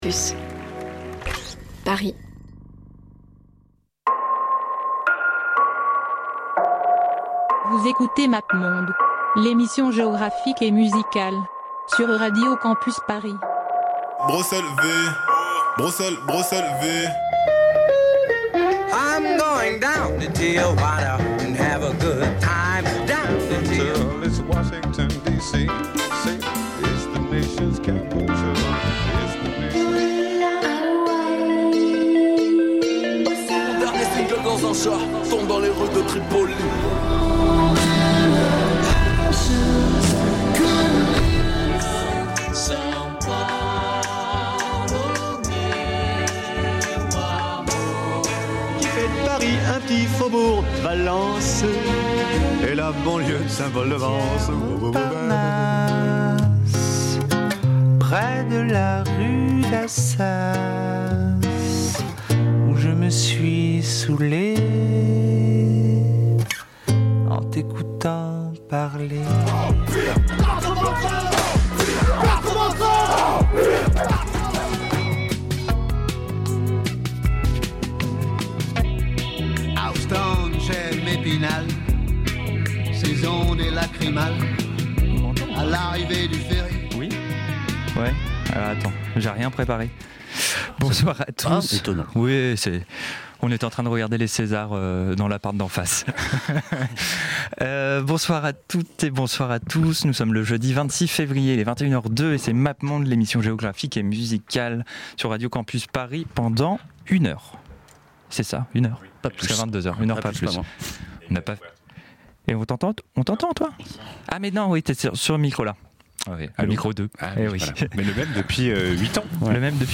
Il y a du métal mais pas que.